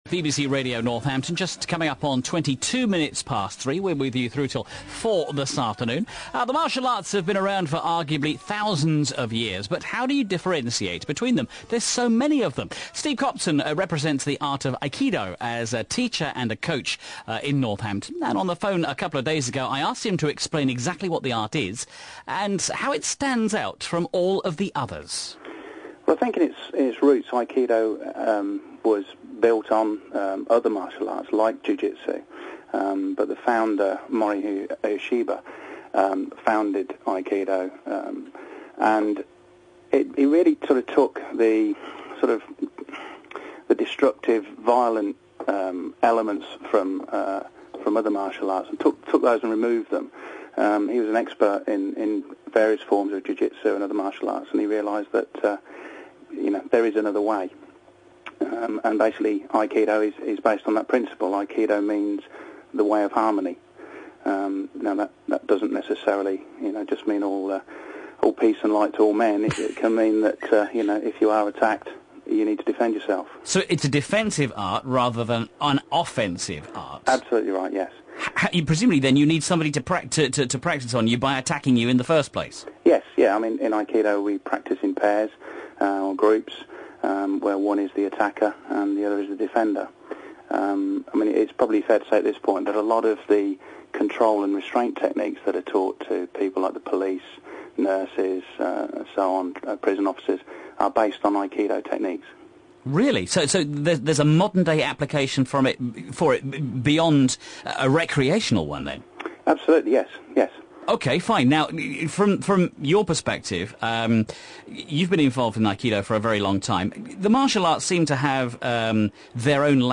radio-northampton.wav